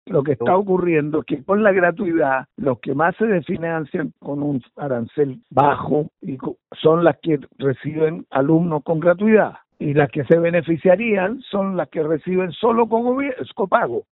Al respecto, y consultado por Bío Bío La Radio, el exministro de Educación, Sergio Bitar, indicó que las casas de estudios que reciben alumnos con gratuidad hoy se están desfinanciando.